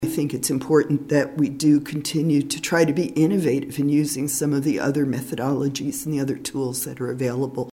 Commissioner Susan Adamchak also voiced her support for several proposals.